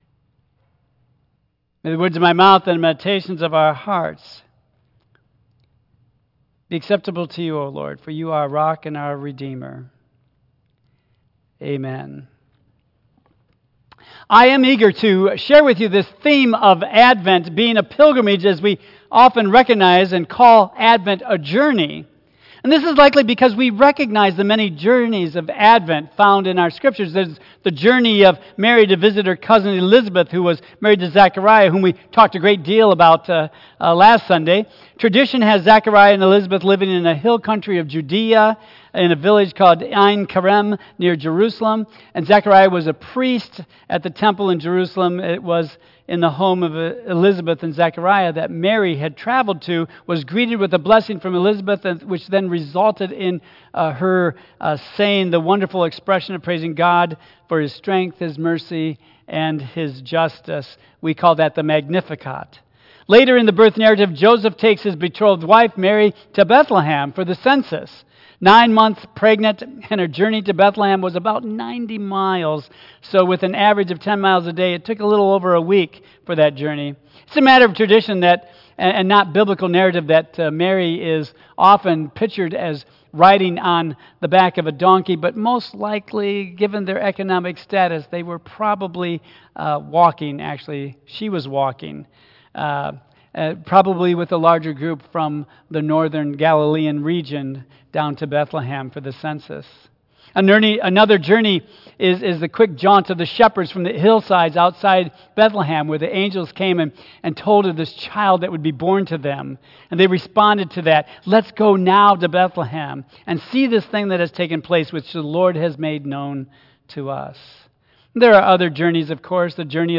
Tagged with 2025 , Advent , Michigan , Sermon , Waterford Central United Methodist Church , Worship